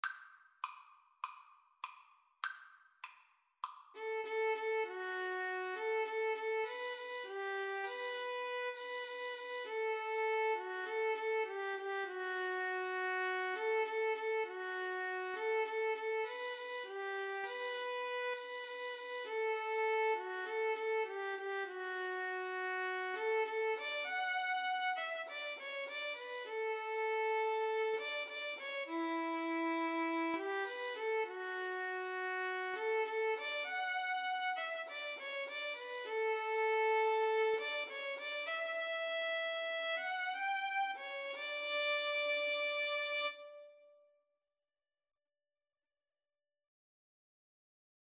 Violin 1Violin 2
4/4 (View more 4/4 Music)